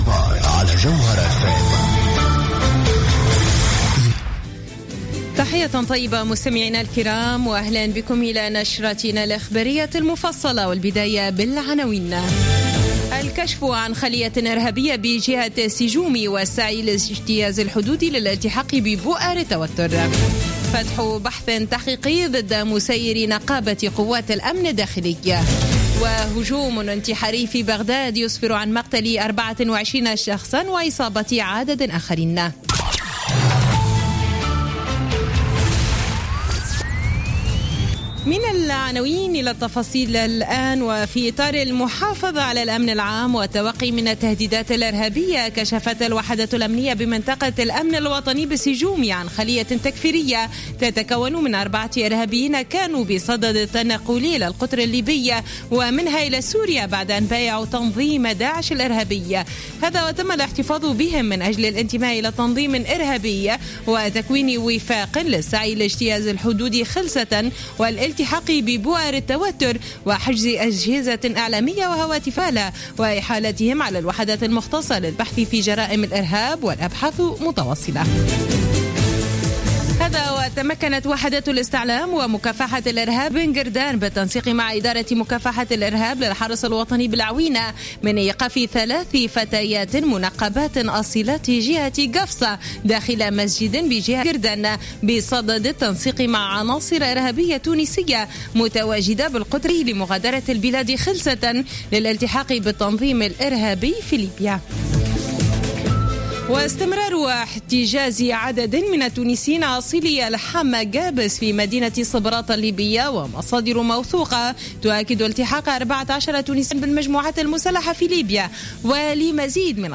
نشرة أخبار السابعة مساء ليوم الإثنين 29 فيفري 2016